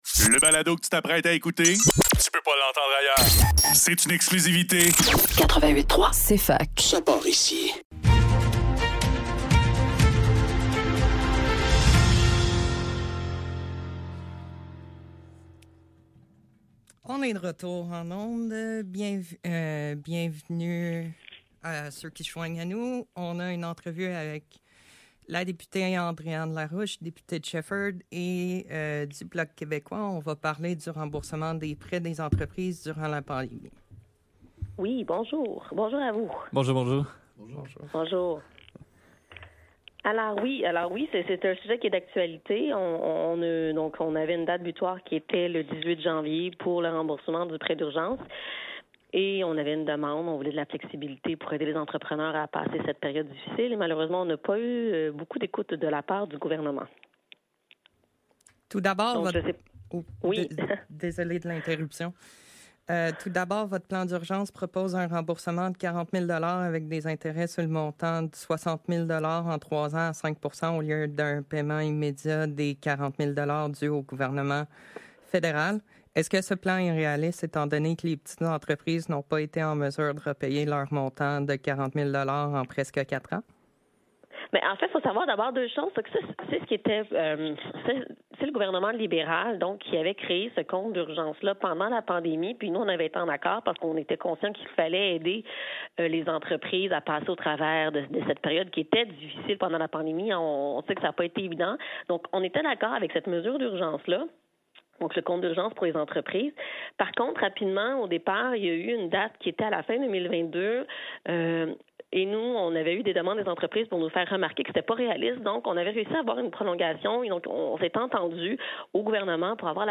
Le NEUF - Entrevue avec Andréanne Larouche - 30 janvier 2024